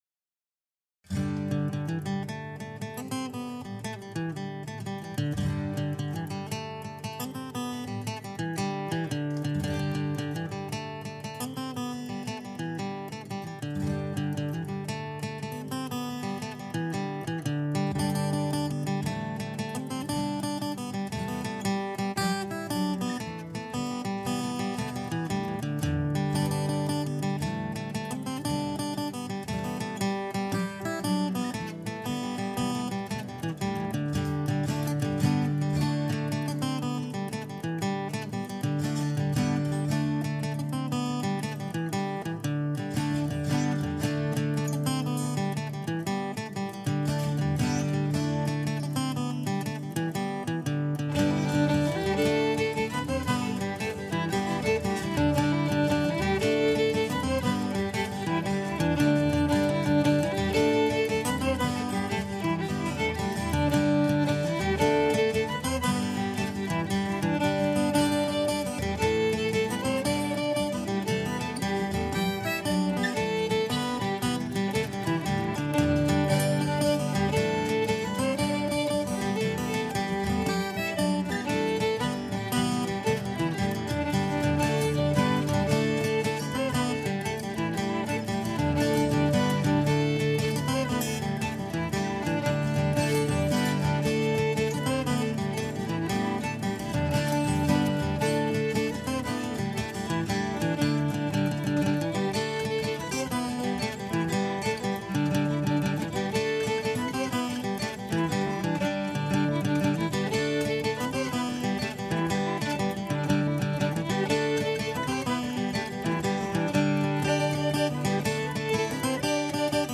mandolin
guitar
fiddle